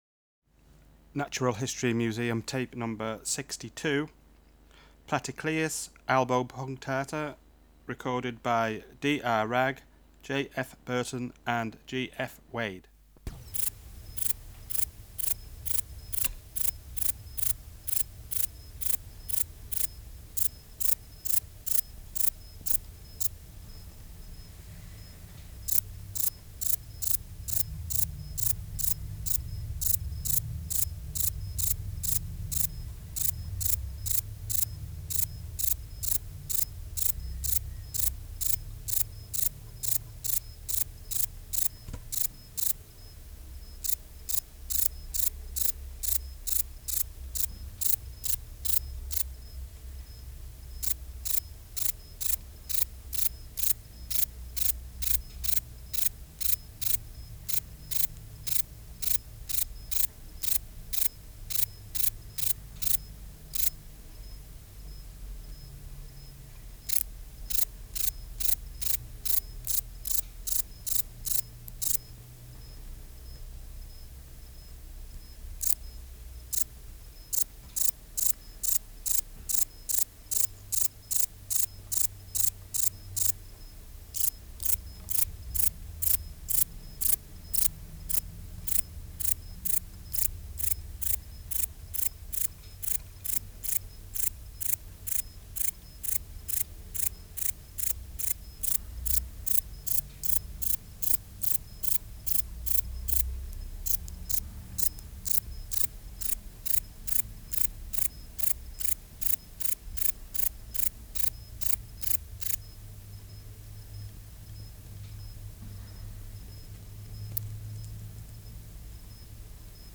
Species: Platycleis albopunctata
Extraneous Noise: Squeaking tape recorder Substrate/Cage: Muslin-covered wooden cage
Microphone & Power Supply: STC 4037A Recorder Recorder: Kudelski Nagra III Tape: Emitape 4